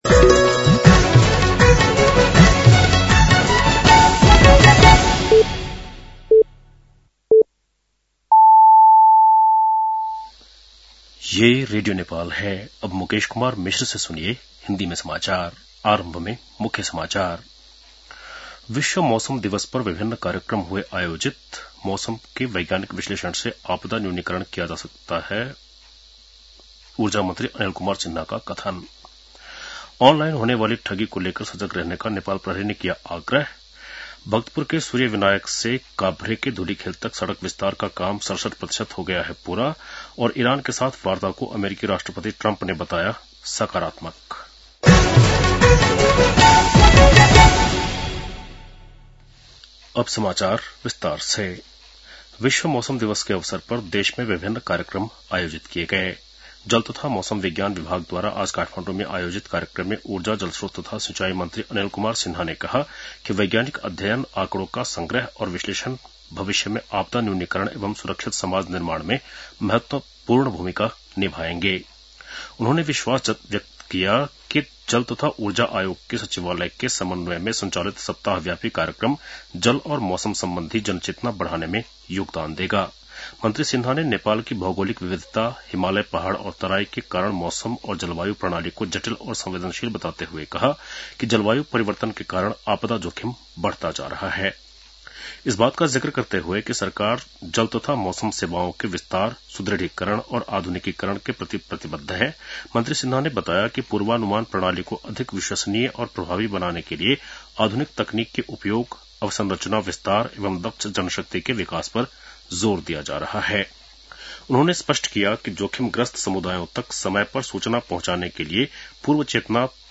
बेलुकी १० बजेको हिन्दी समाचार : ९ चैत , २०८२